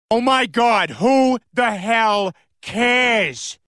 who the hell cares family guy peter Meme Sound Effect
Category: Reactions Soundboard